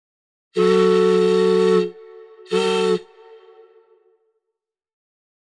train-whistle.wav